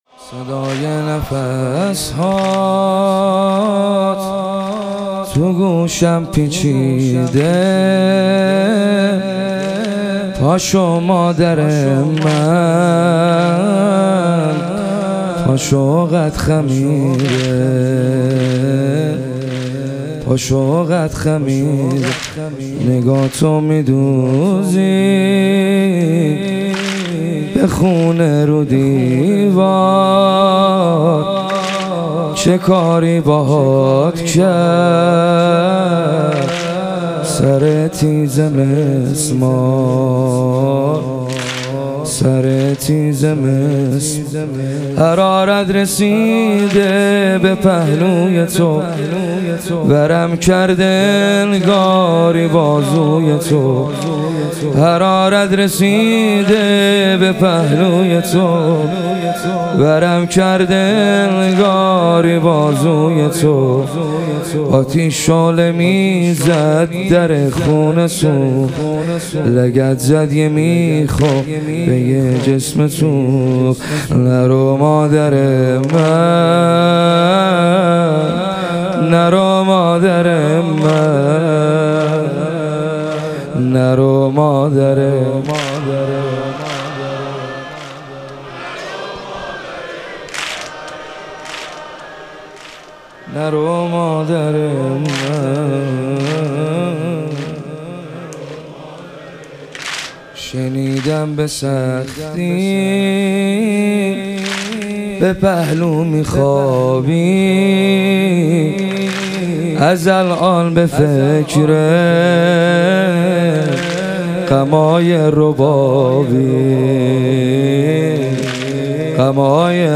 ایام فاطمیه دوم - واحد